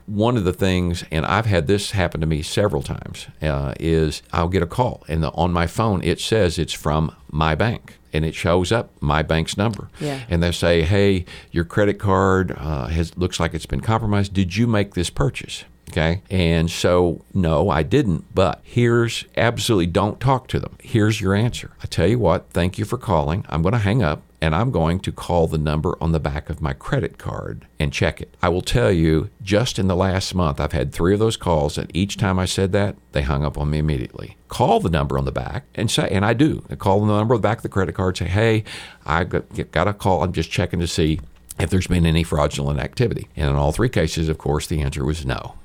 In response to a surge in scams targeting Baxter County residents, KTLO hosted Sheriff John Montgomery Thursday morning for a live call-in program aimed at educating the public on how to avoid common scams.
During the broadcast, Sheriff Montgomery outlined numerous red flags residents should watch for and gave advice on what to do if someone thinks they are being scammed.